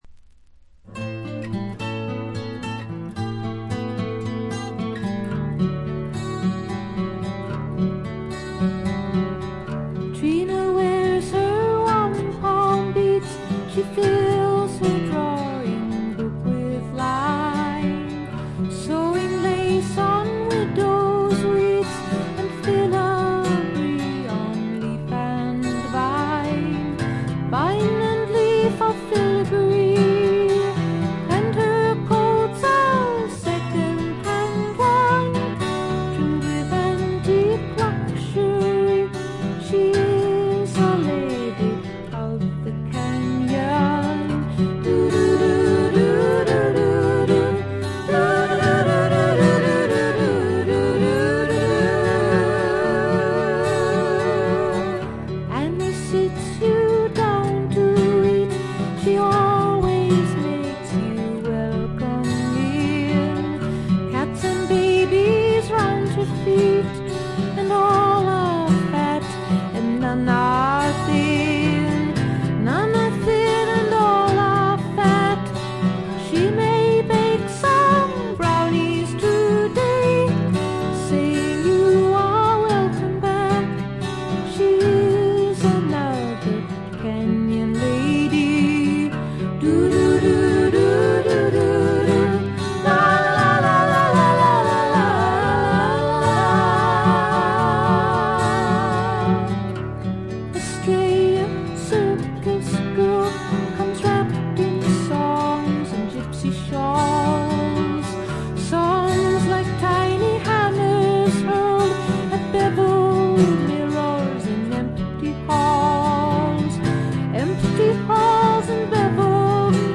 試聴曲は現品からの取り込み音源です。